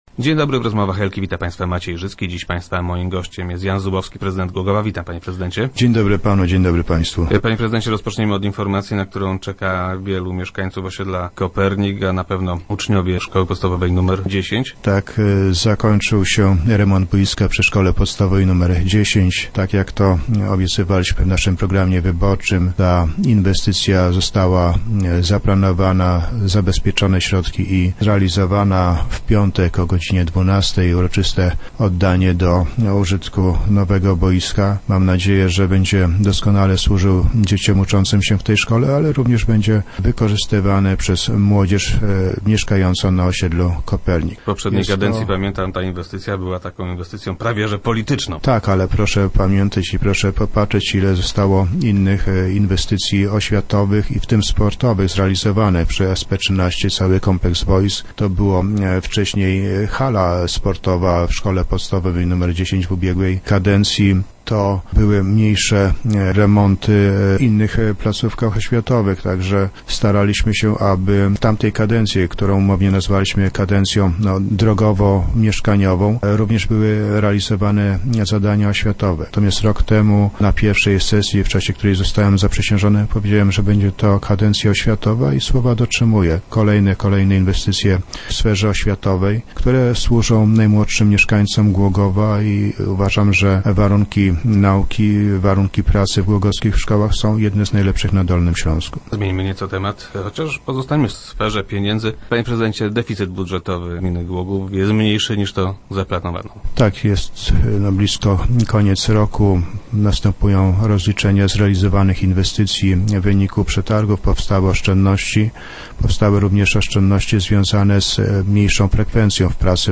- W ostatnich miesiącach roku okazało się jednak, że będzie on zdecydowanie niższy - poinformował prezydent Jan Zubowski, który był gościem Rozmów Elki.
Po nich okaże się, że deficyt budżetowy wyniesie do pięciu milionów złotych - mówił na radiowej antenie prezydent.